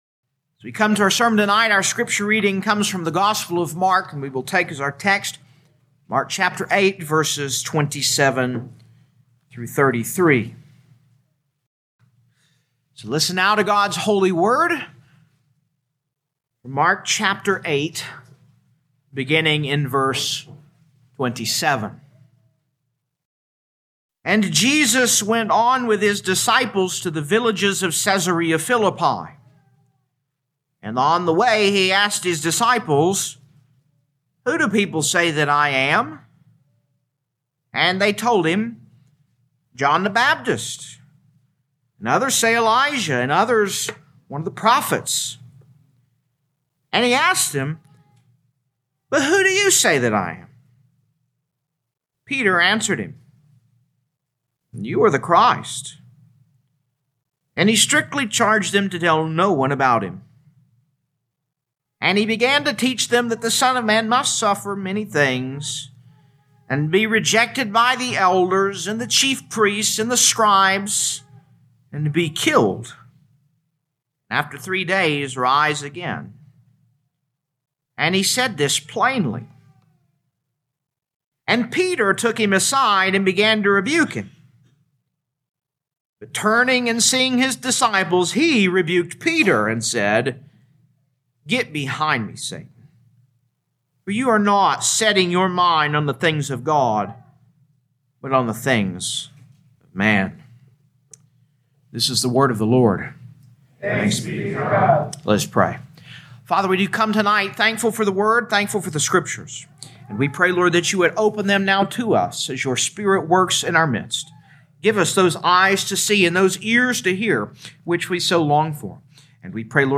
2026 Mark Evening Service Download: Audio Bulletin All sermons are copyright by this church or the speaker indicated.